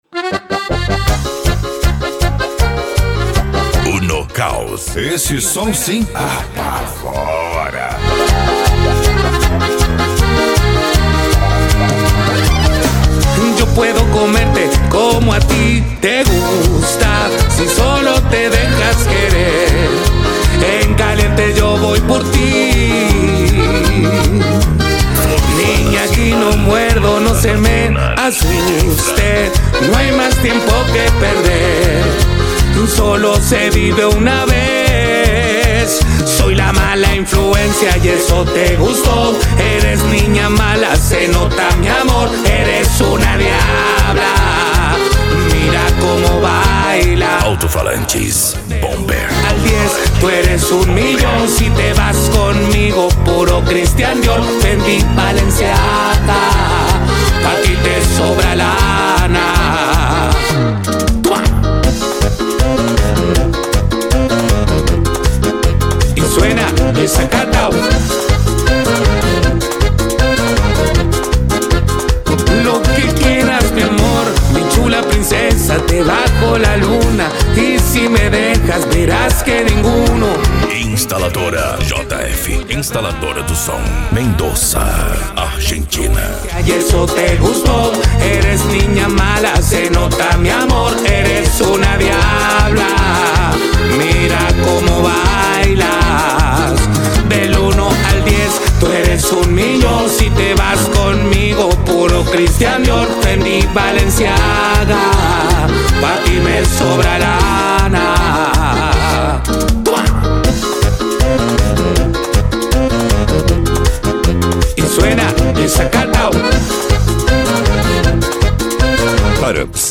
Bass
Musica Electronica
Remix